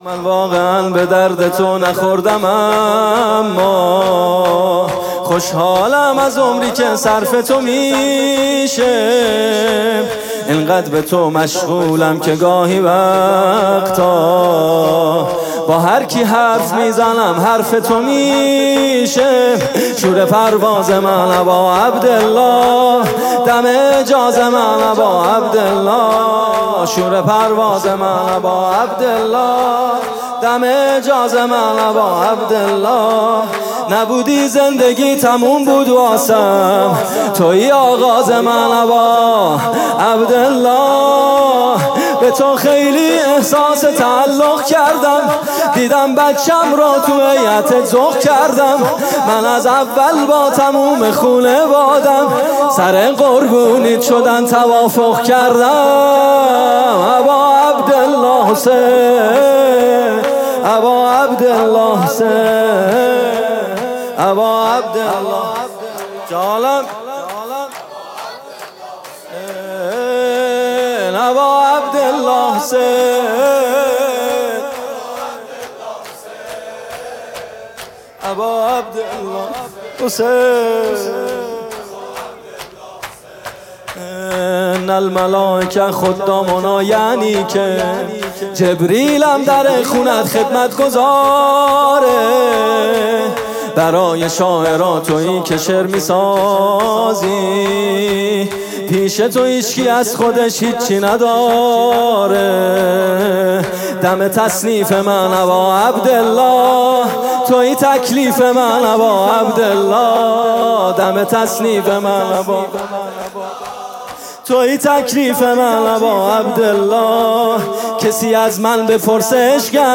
music-icon روضه